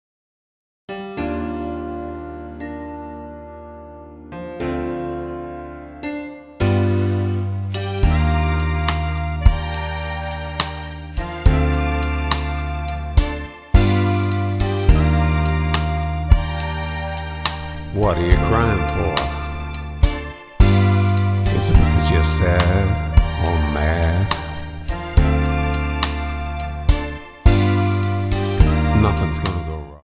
Tonart:Bb Multifile (kein Sofortdownload.